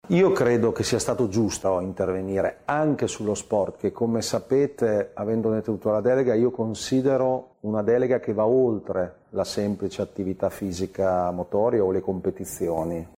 L’importanza di puntare sullo sport anche nella fase di ricostruzione è spiegata da Stefano Bonaccini, presidente Regione Emilia Romagna.